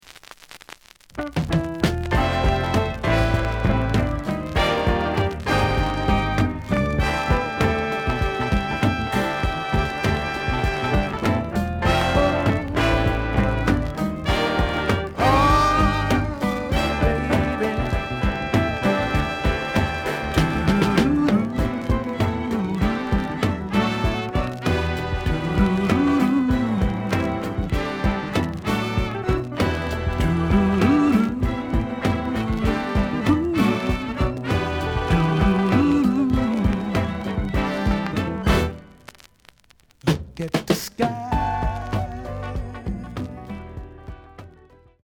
The listen sample is recorded from the actual item.
●Format: 7 inch
●Genre: Soul, 70's Soul